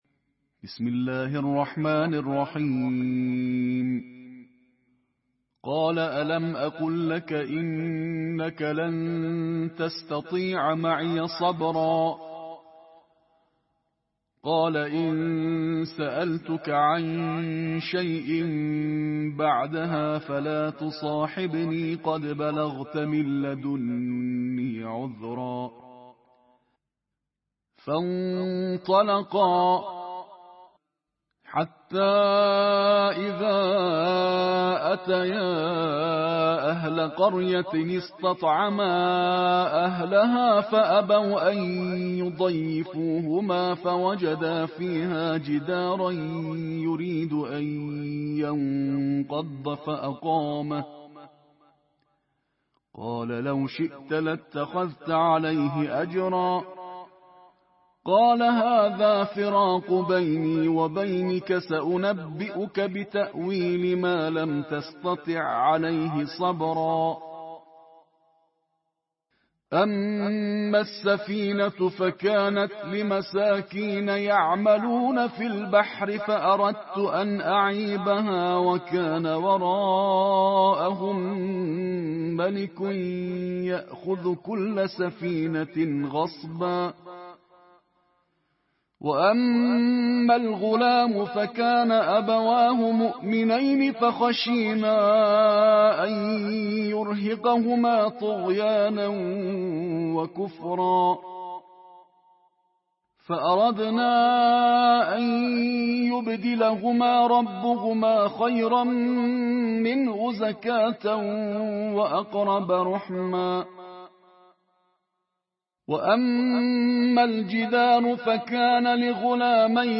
Қироати тартили ҷузъи 16-уми Қуръон бо садои қориёни байналмилалӣ + садо